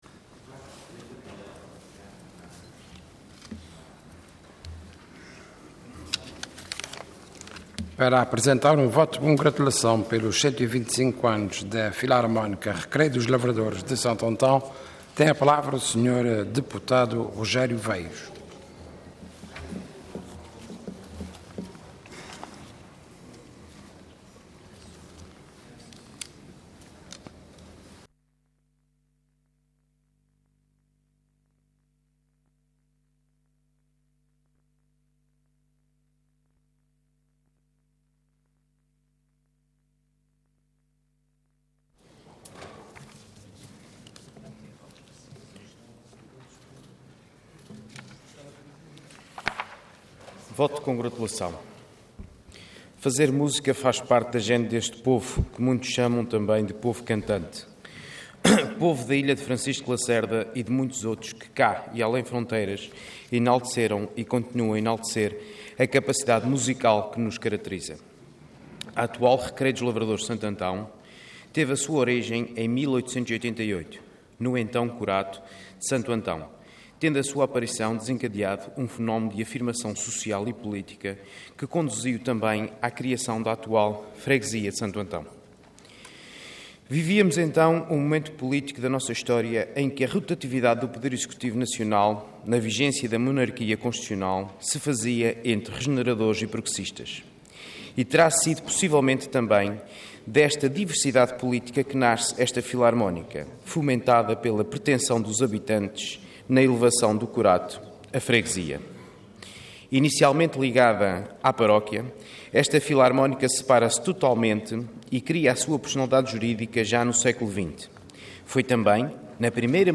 Website da Assembleia Legislativa da Região Autónoma dos Açores
Intervenção Voto de Congratulação Orador Rogério Veiros Cargo Deputado Entidade PS